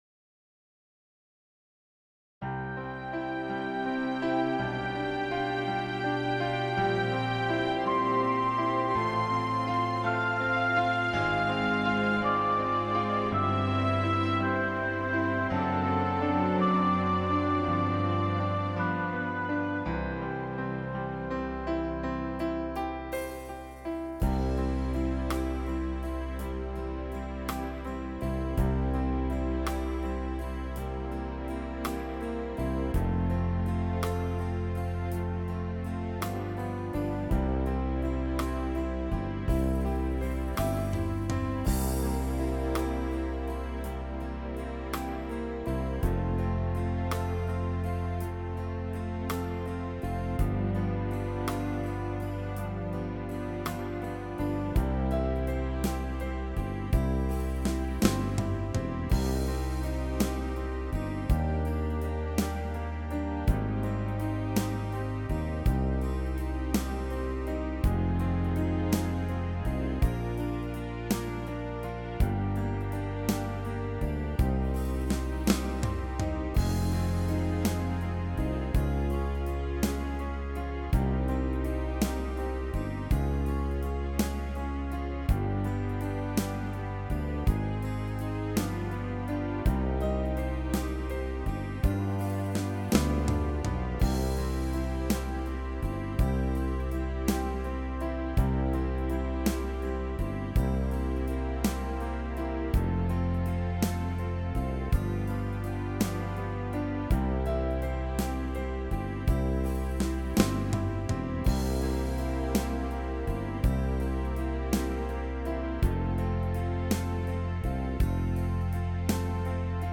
Nữ (Cm)